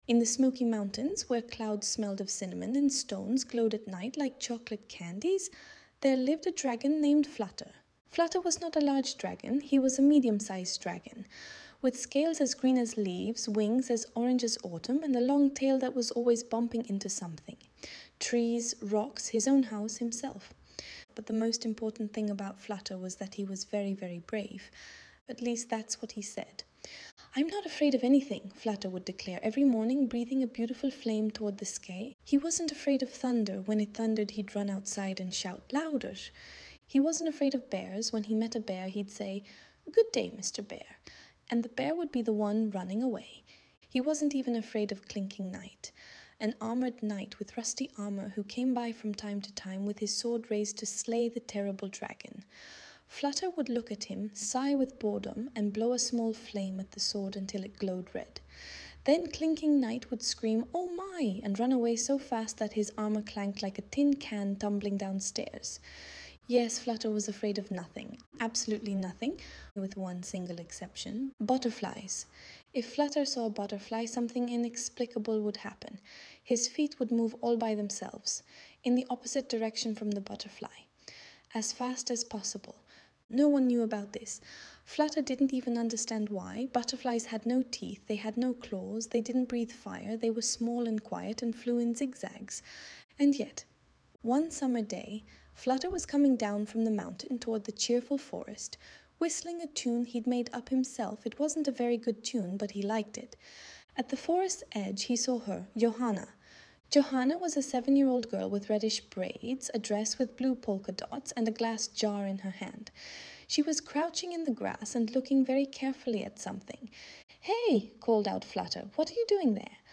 audiobook the Fluttering Dragon Who Was Afraid of Butterflies
Audiobook_Flutter.mp3